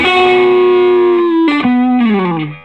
Index of /90_sSampleCDs/USB Soundscan vol.22 - Vintage Blues Guitar [AKAI] 1CD/Partition C/13-SOLO B060